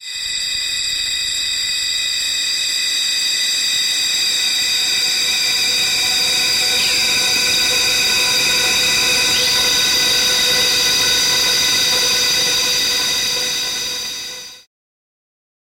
The sounds are mostly space-age, weird naughty noises, and buzzy things -- cutting edge for 1976.